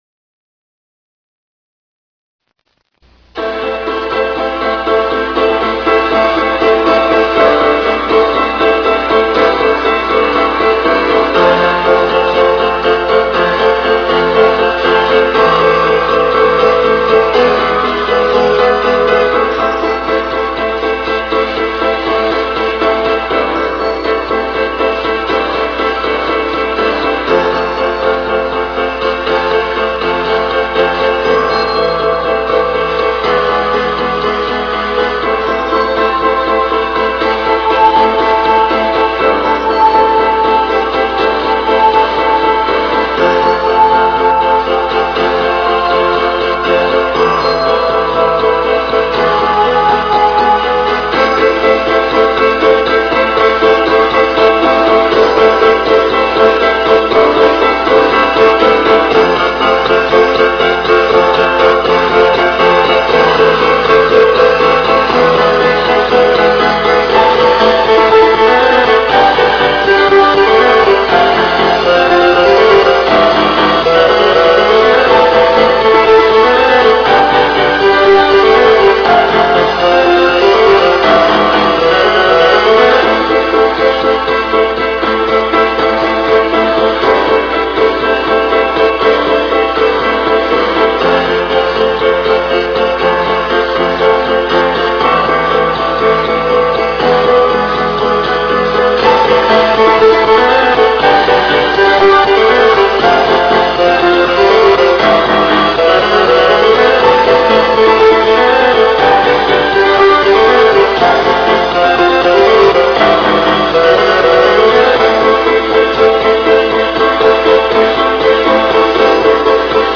The first three pieces were created using a keyboard synthesizer and a computer. They were sequenced using multiple tracks and edited on the computer using Cubase software.